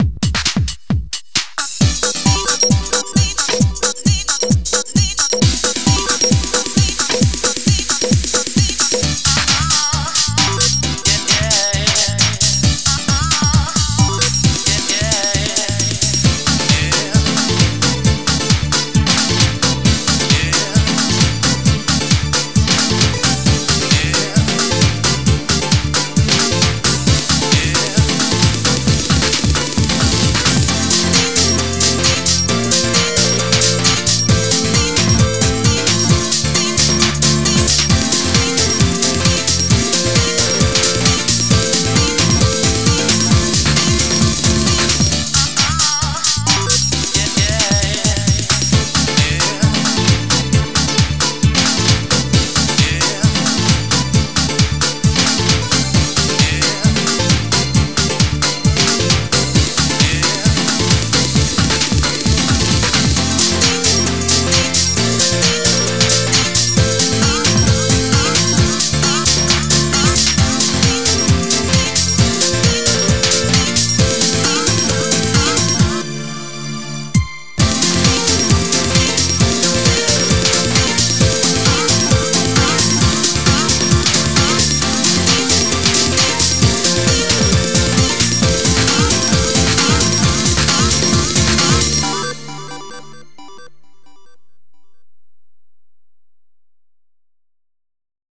BPM133
Better quality audio.